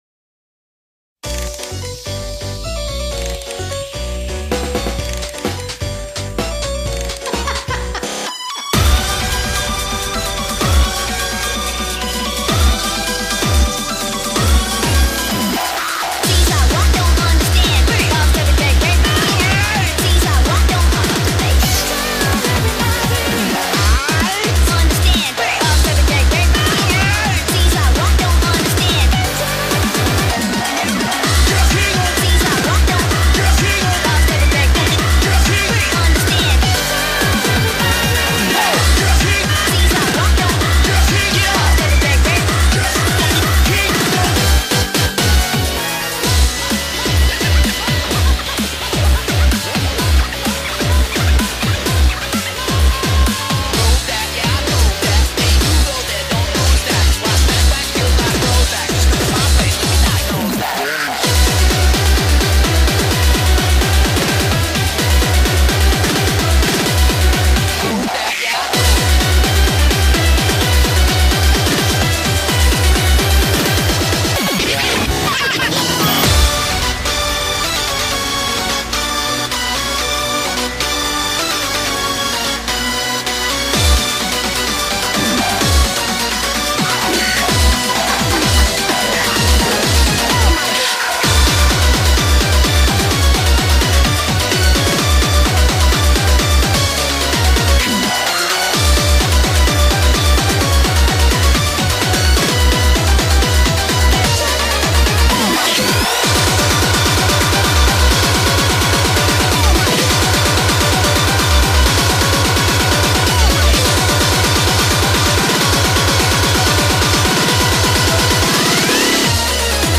BPM128-256
Audio QualityLine Out
It's a pretty catchy song.